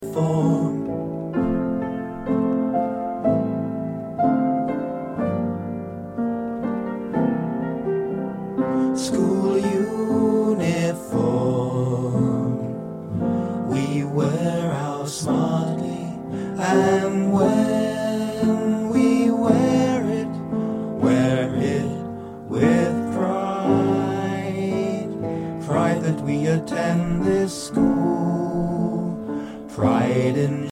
Listen to the vocal track.